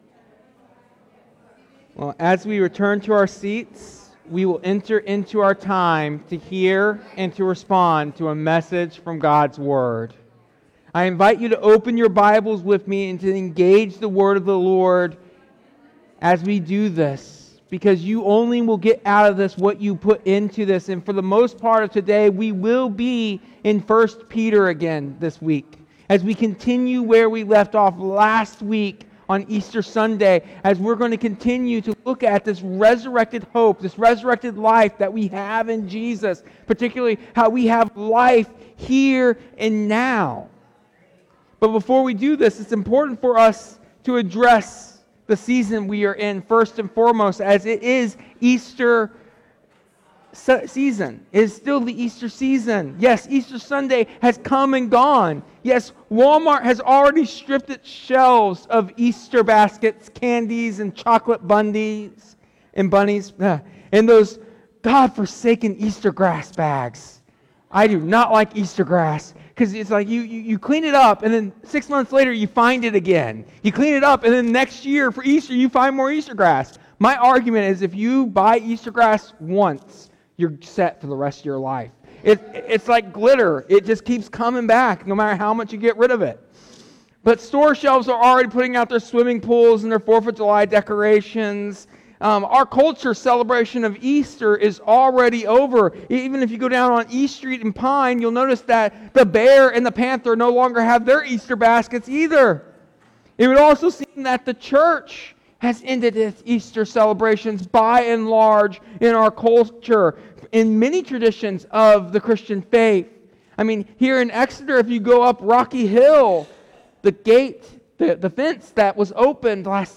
This Easter sermon emphasizes that Jesus’ resurrection brings not only a future hope but a present “living hope” (1 Peter 1:3).